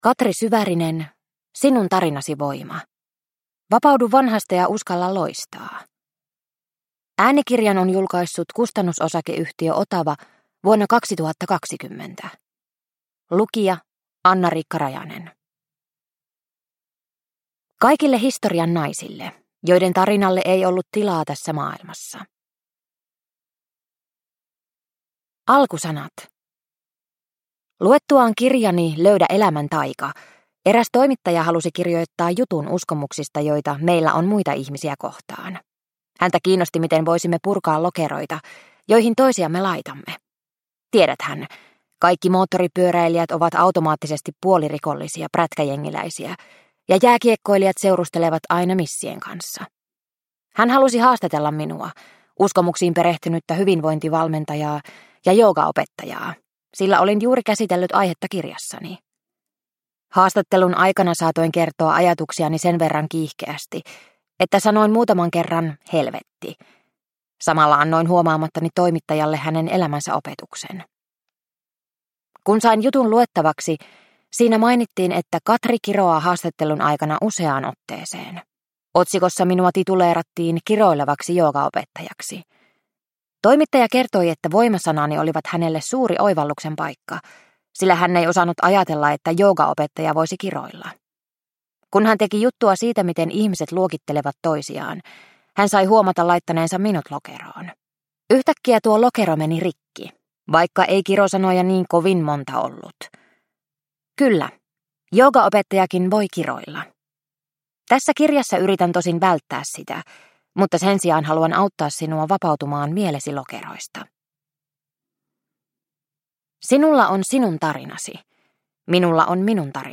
Sinun tarinasi voima – Ljudbok – Laddas ner